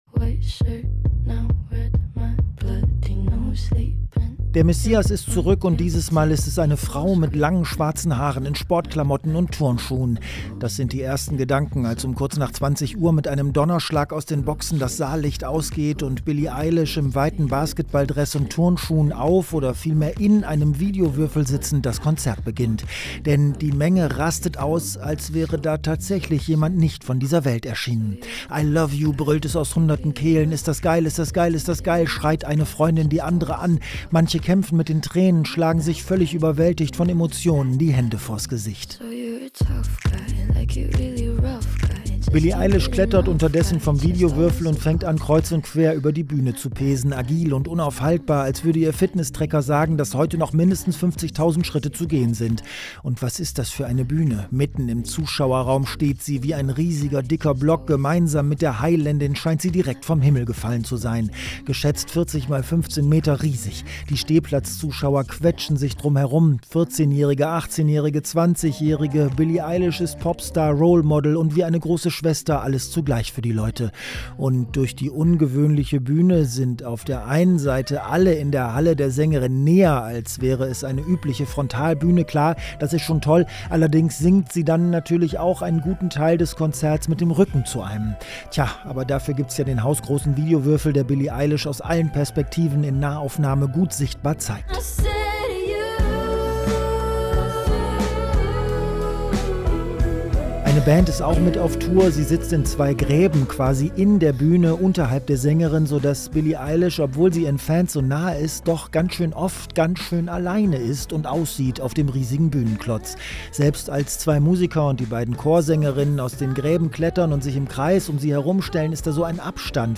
Der Wegweiser durch die weite Kulturlandschaft von Berlin und Brandenburg: Premieren- und Konzertkritiken, Film- und Musiktipps, Rundgänge durch aktuelle Ausstellungen.